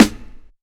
Quick Snare.wav